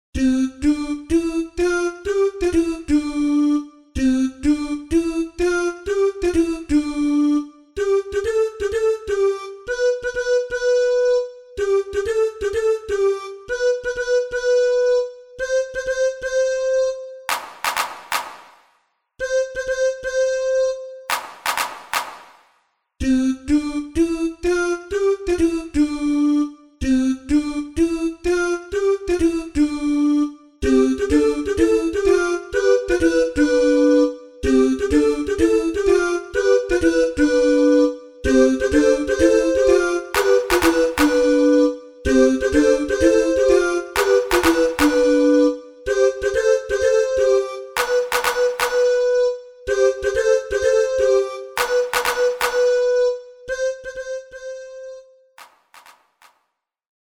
RÉPERTOIRE  ENFANTS
CANONS